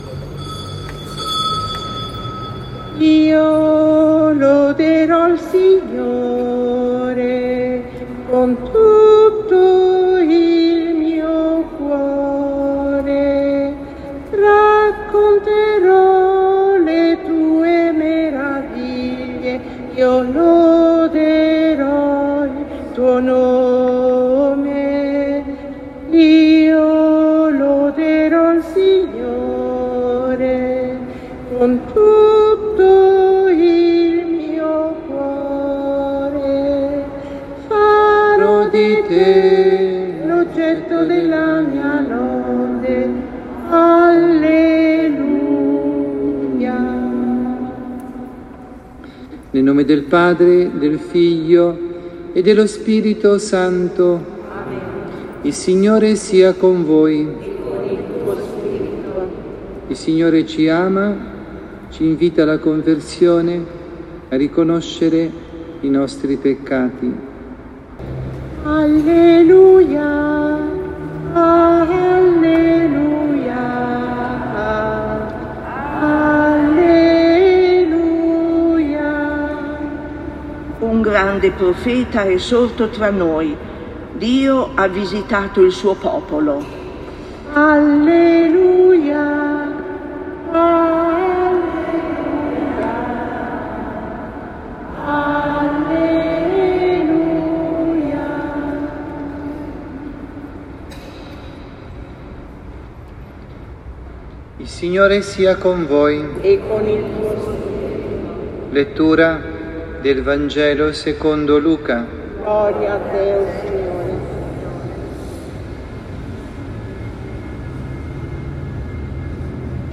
dalla Parrocchia Santa Rita – Milano